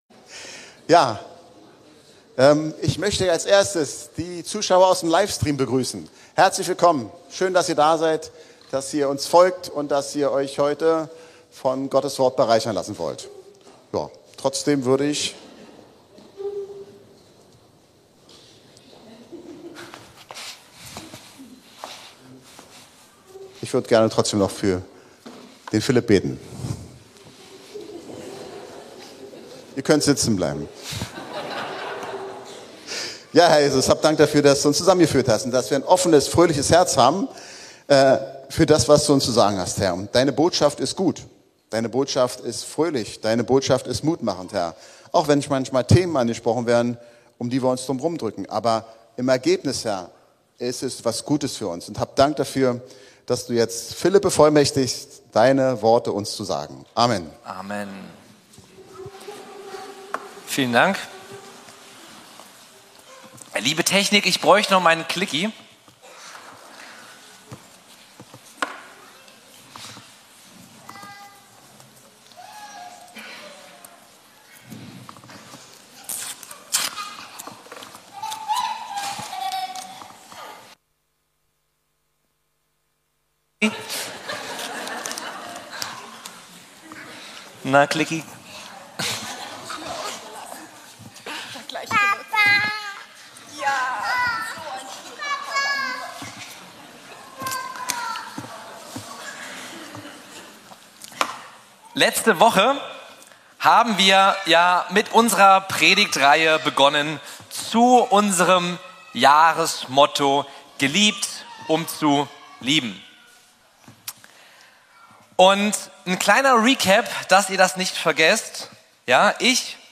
Teil 2 der Predigtreihe zum Jahresmotto 2026